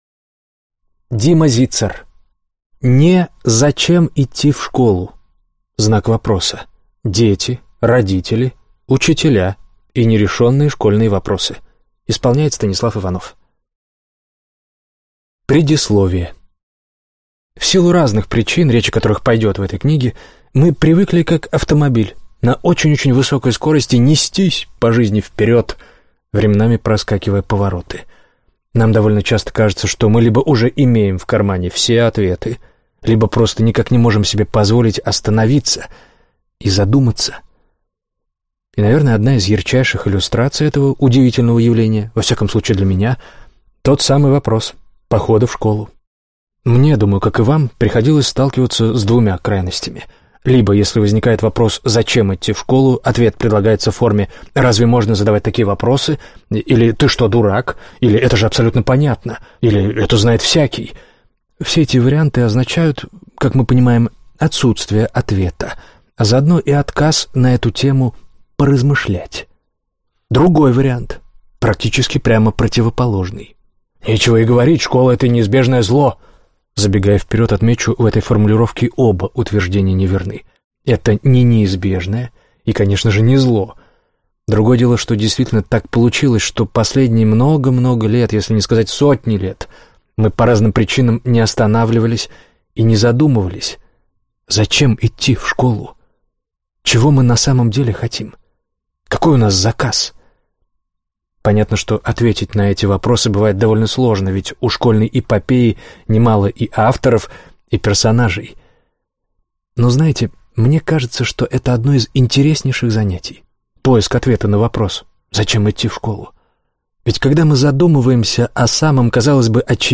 Аудиокнига (Не) Зачем идти в школу? Дети, родители, учителя и нерешенные школьные вопросы | Библиотека аудиокниг